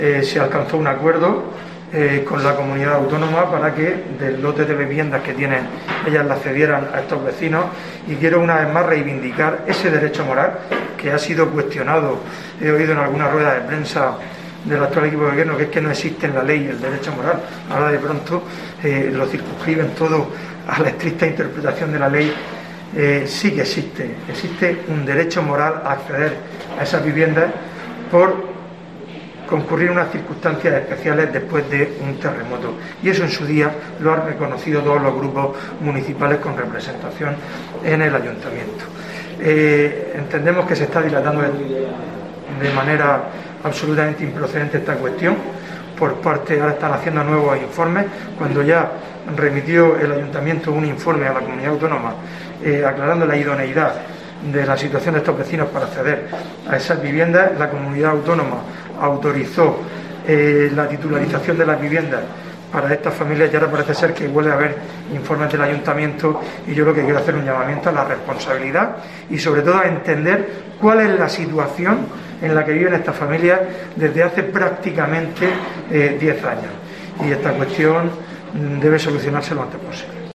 Fulgencio Gil, portavoz del PP de Lorca